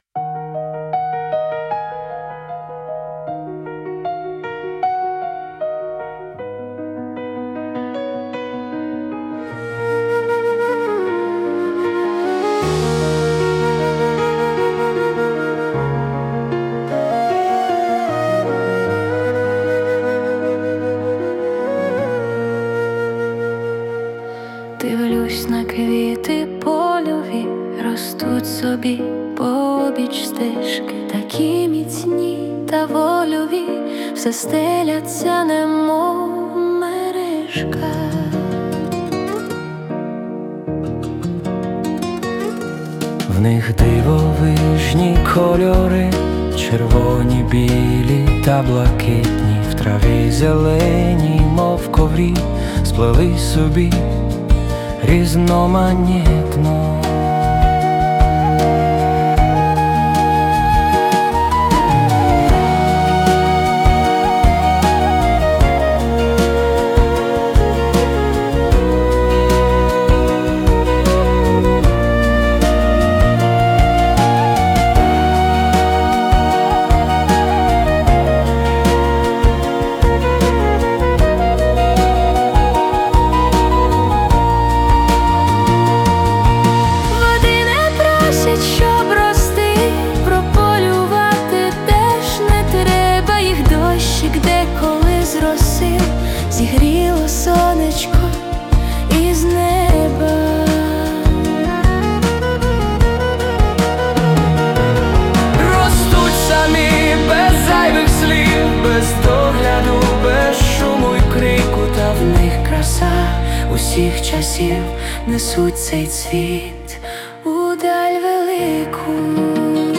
🎵 Жанр: Lyrical Ballad
ніжна, молитовна балада